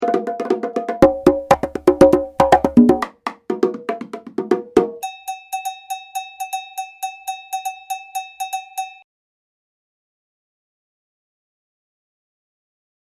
bongosandbells.mp3